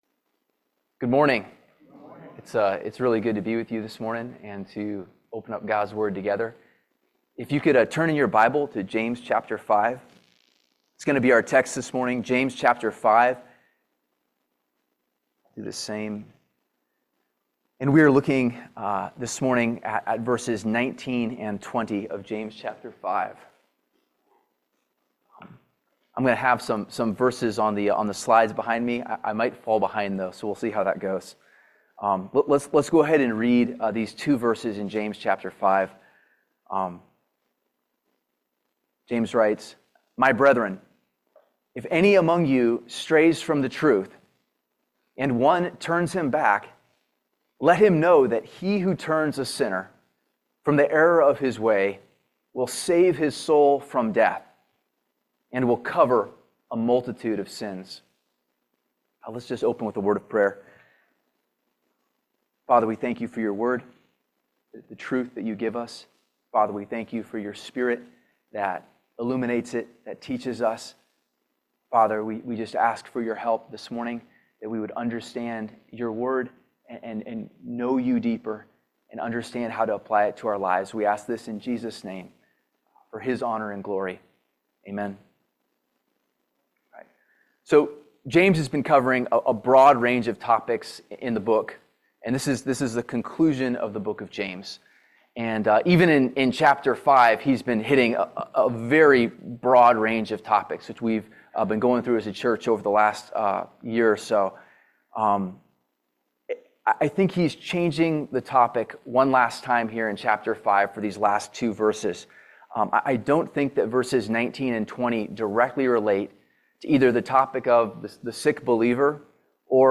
Teaching from James 5:19,20
James 5:19,20 Service Type: Family Bible Hour God demonstrates His love when one turns a sinner to repentance.